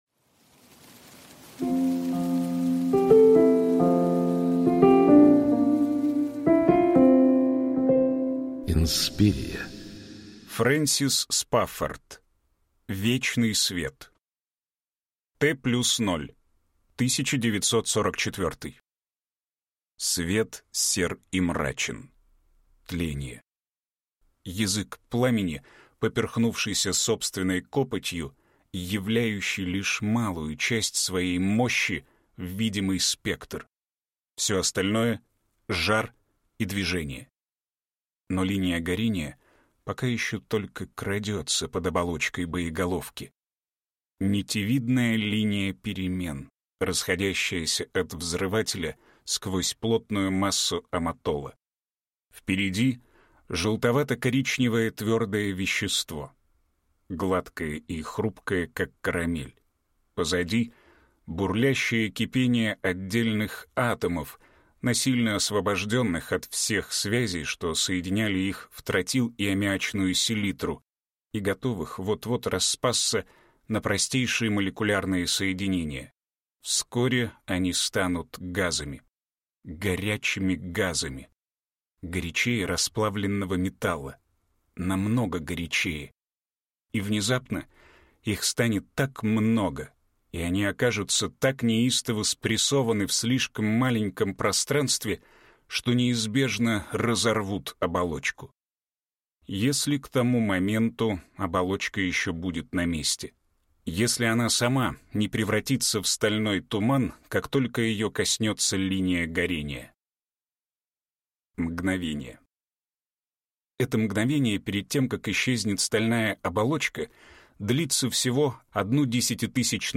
Аудиокнига Вечный свет | Библиотека аудиокниг